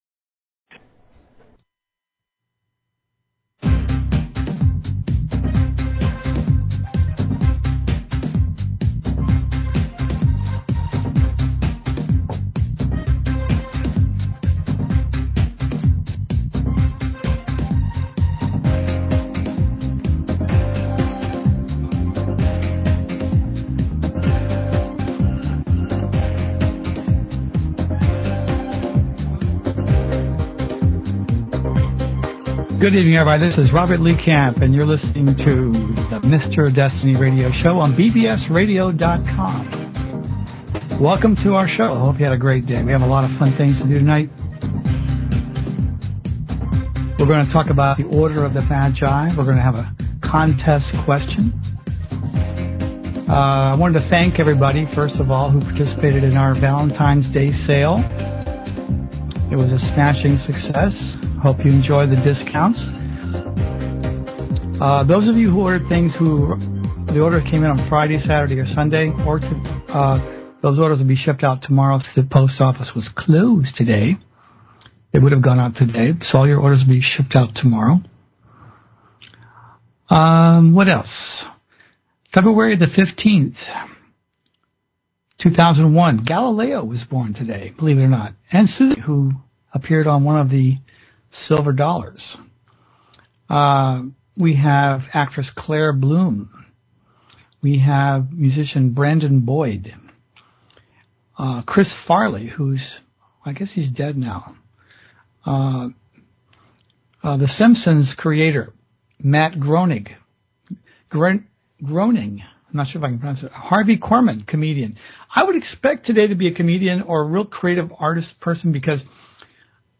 Talk Show Episode
Predictions and analysis. Guest interview or topic discussion.